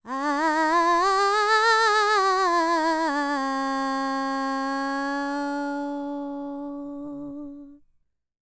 QAWALLI 11.wav